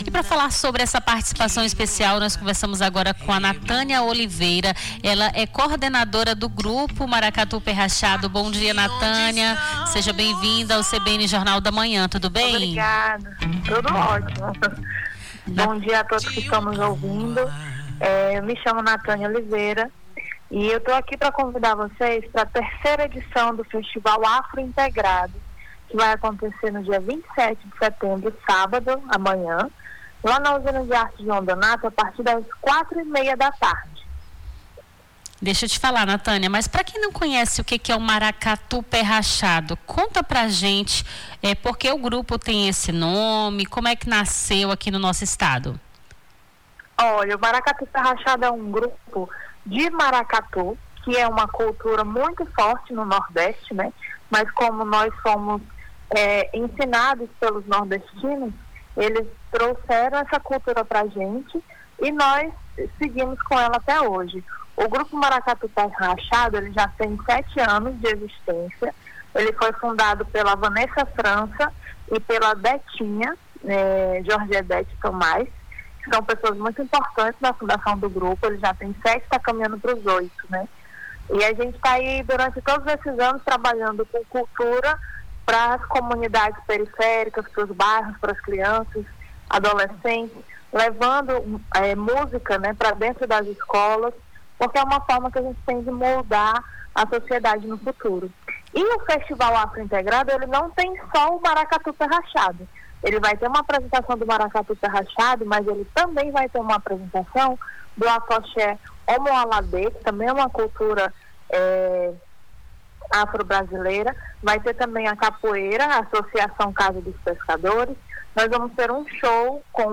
Nome do Artista - CENSURA - ENTREVISTA (FESTIVAL AFRO INTEGRADO - MARACATU PÉ RACHADO) 26-09-25.mp3